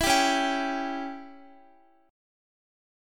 Dbdim chord